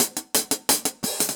Index of /musicradar/ultimate-hihat-samples/175bpm
UHH_AcoustiHatC_175-02.wav